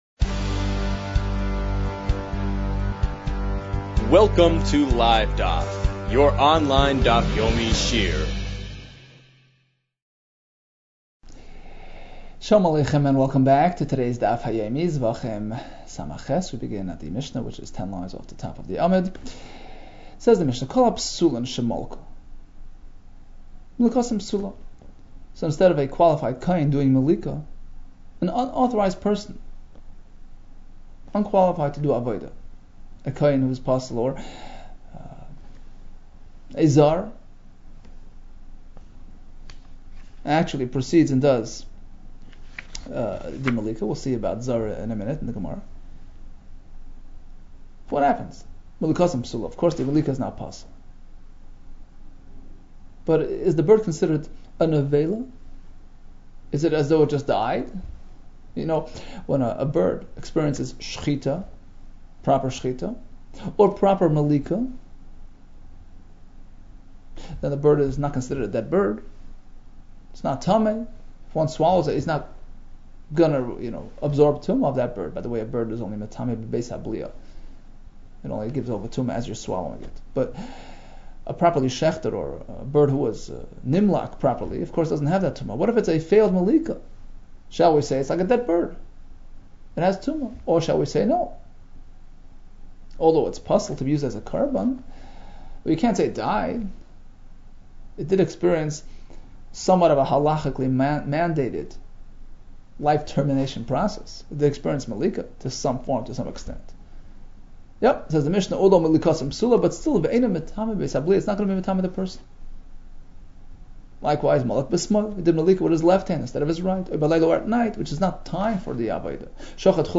Zevachim 67 - זבחים סז | Daf Yomi Online Shiur | Livedaf